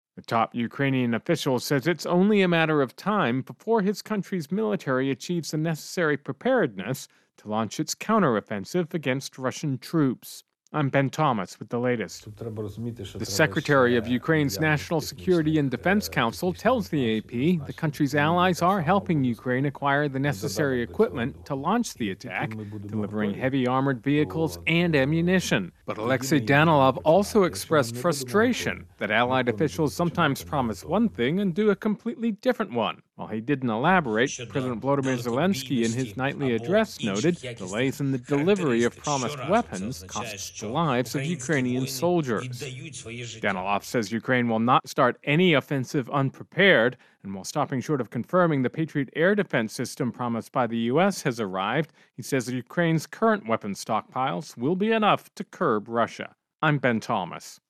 Interview.